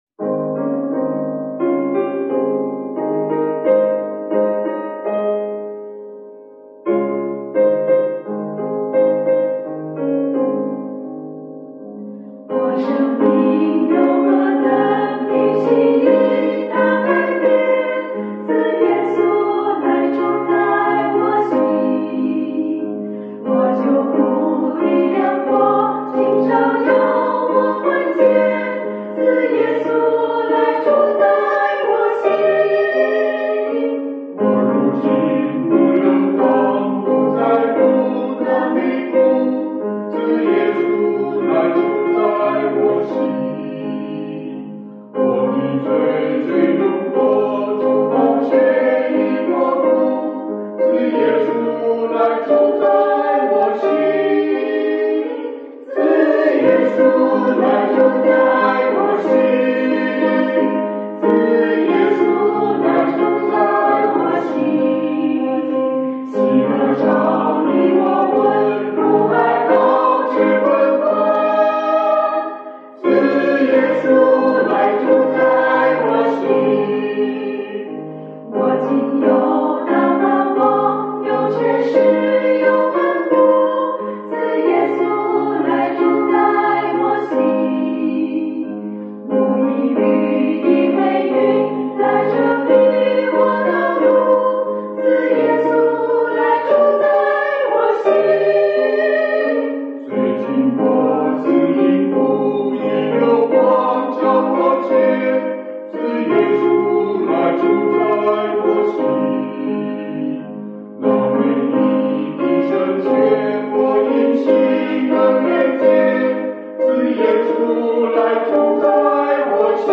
赞美诗 | 自耶稣住在我心
词：Rufus H.McDANIEL
曲：Rles H.GABRIEL